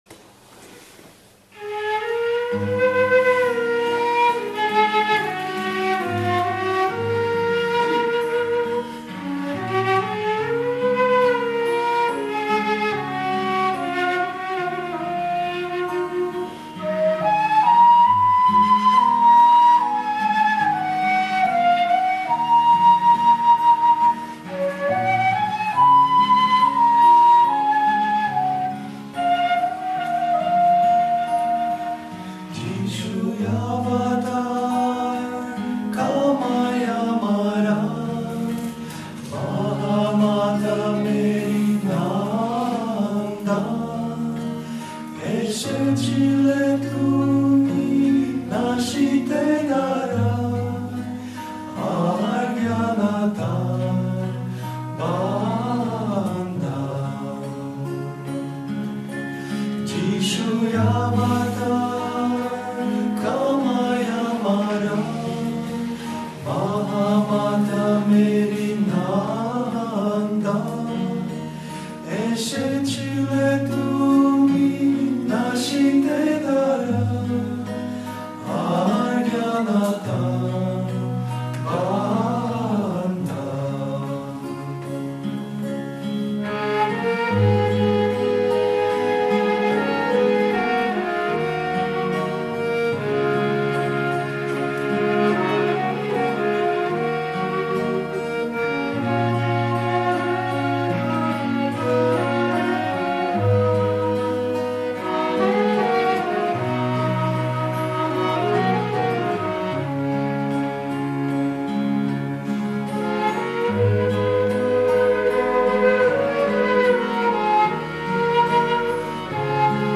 meditative songs